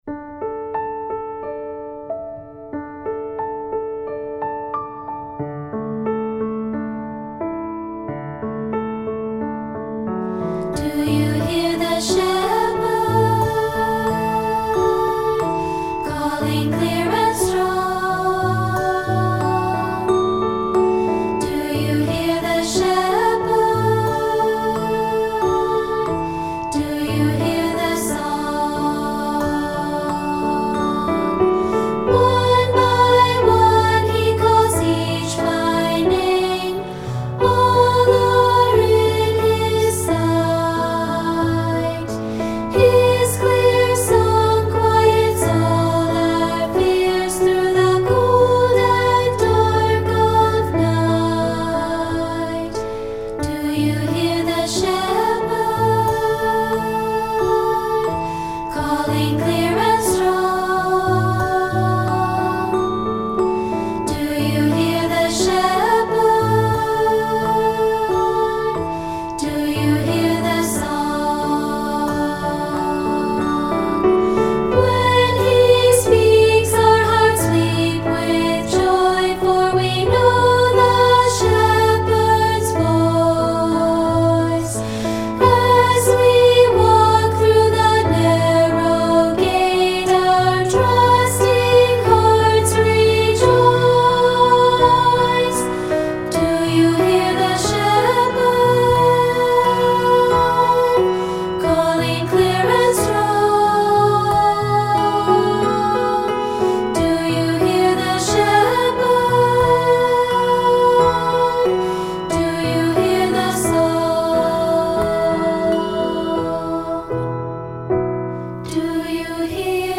Voicing: Unison/2-Part and Piano